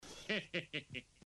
risata Homer
Guardando i Simpson mi è rimasta in mente la "risata diabolica" di Homer.
risata_homer.wav